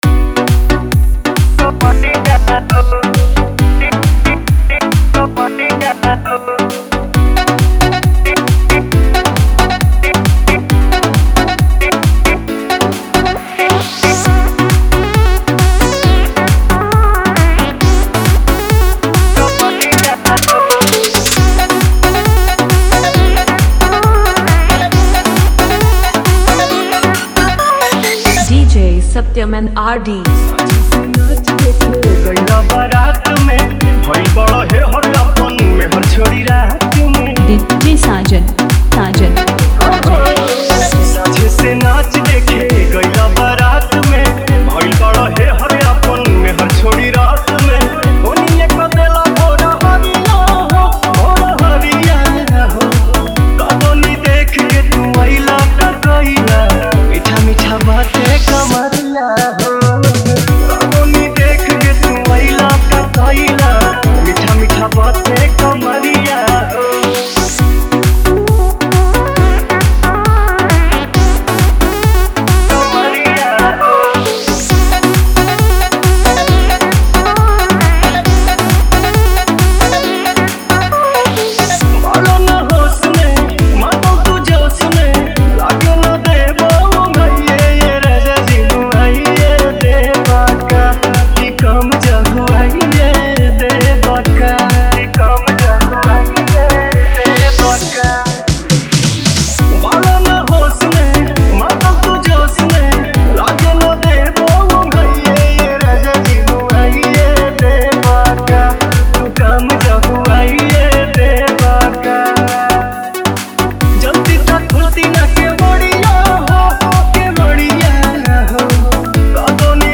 Category : Bhojpuri DJ Remix Songs